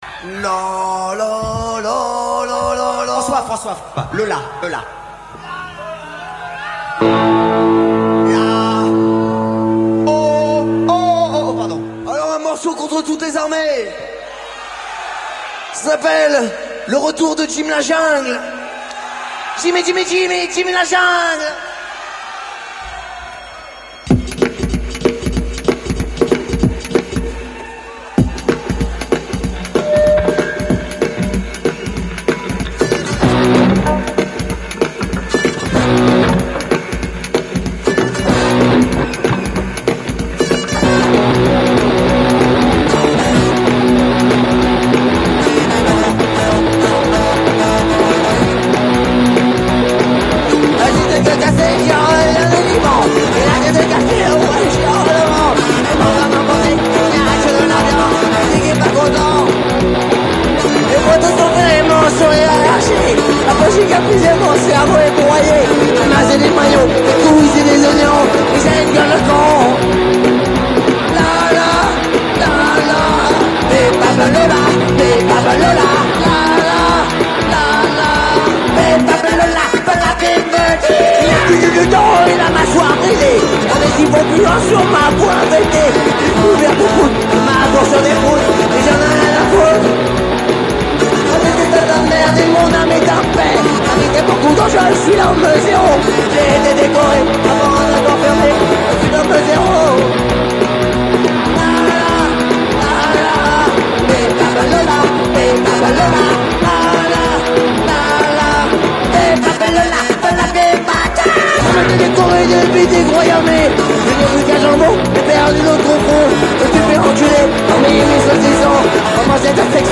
Definitely one if not THE best french punk rock: